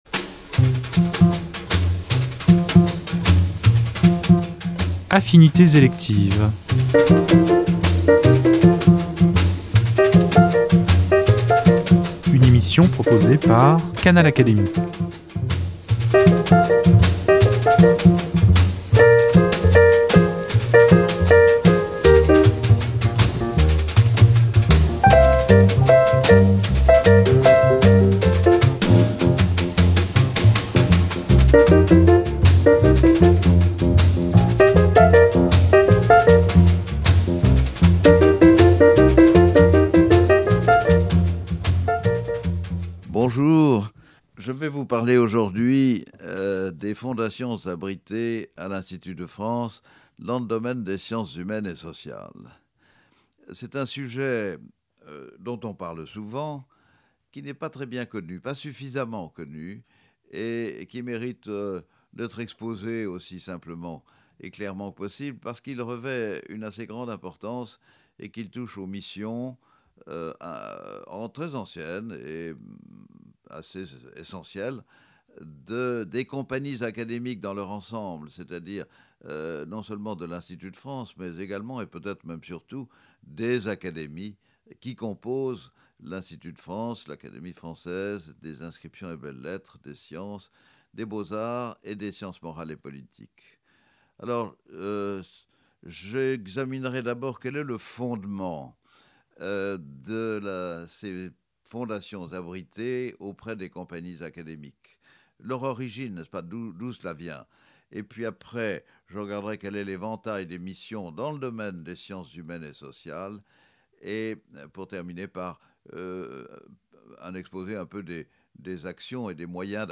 AU lendemain de la remise des Grands Prix des Fondations abritées par l’Institut de France, le chancelier Gabriel de Broglie présente aux auditeurs de Canal Académie l’action de celles-ci dans le domaine des sciences humaines et sociales. Dans cette allocution inédite qu'il a donné dans nos studios, il rappelle les origines historiques des fondations de l’Institut, fortement marquées par l’esprit philanthropique des Lumières, mais aussi leur extraordinaire capacité à s’adapter aux enjeux et défis spécifiques de chaque époque, dans la fidélité à la volonté de leurs créateurs.